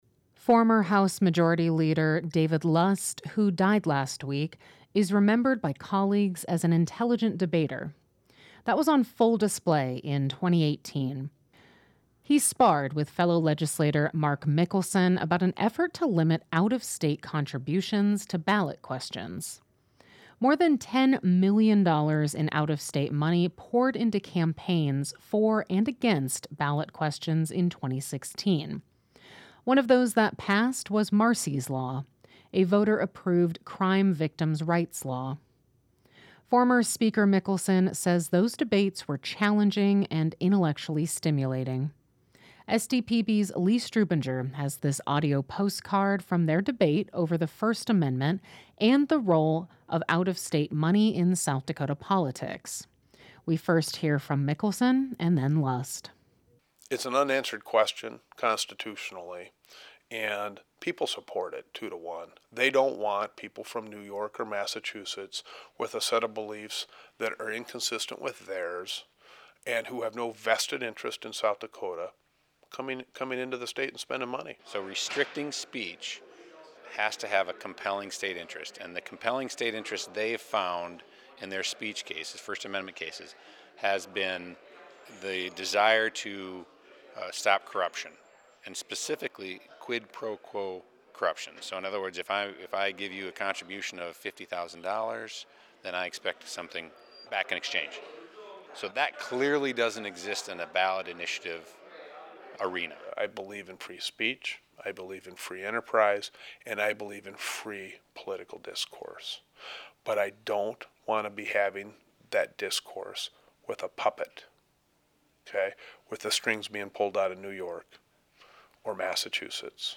That was on full display in 2018. He sparred with fellow legislator Mark Mickelson about an effort to limit out-of-state contributions to ballot questions.
We first hear from Mickelson and then Lust.